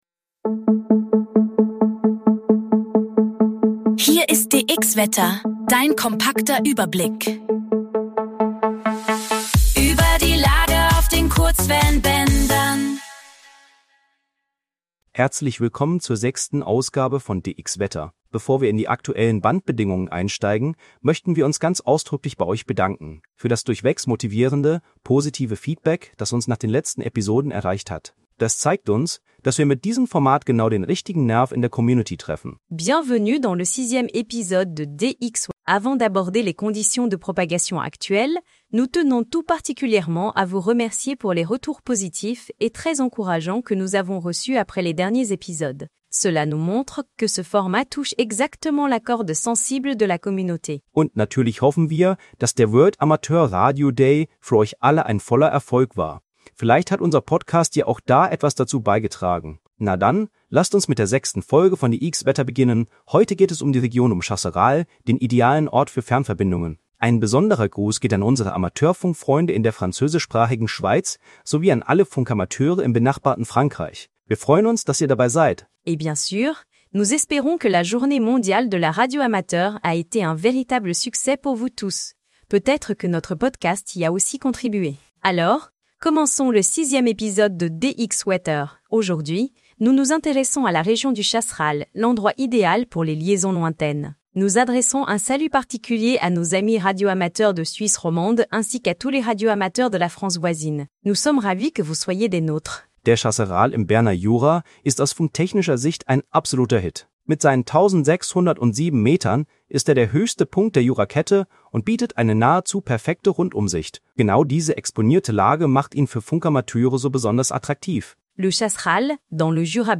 KI-generierter Inhalt - aktuell für die Region Chasseral JU